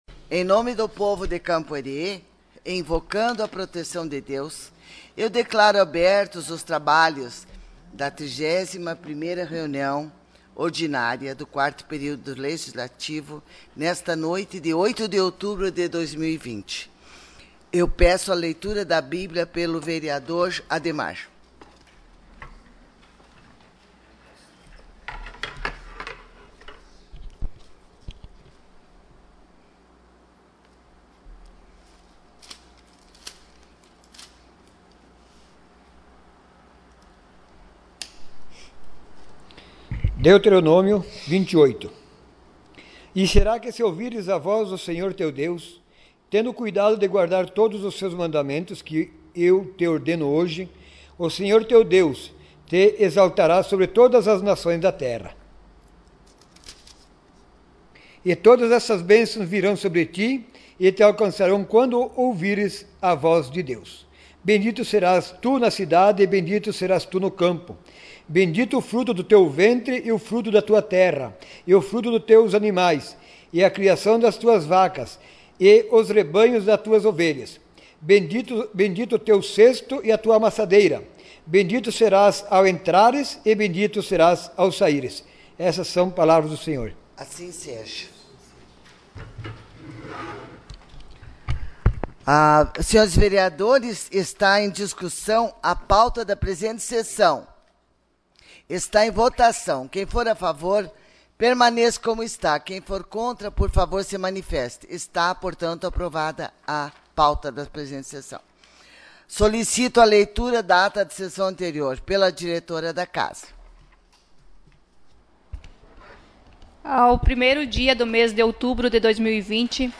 SESSÃO ORDINÁRIA DIA 08 DE OUTUBRO DE 2020